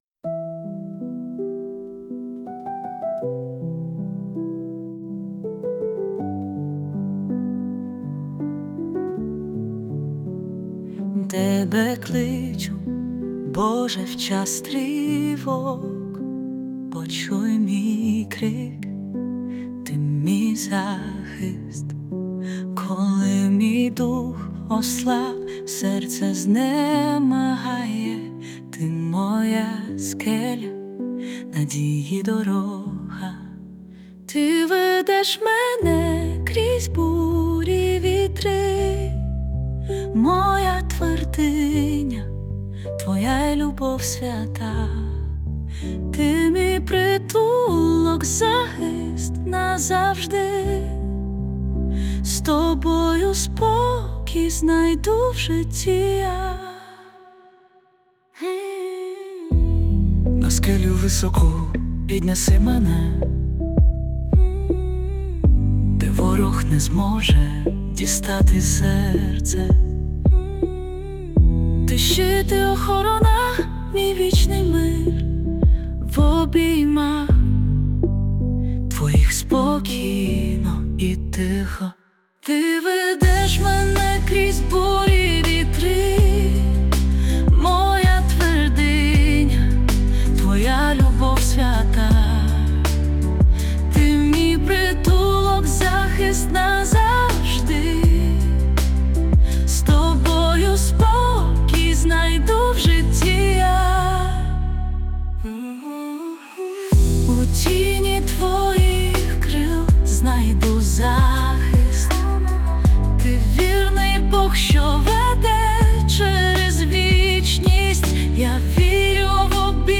песня ai
Jesus Worship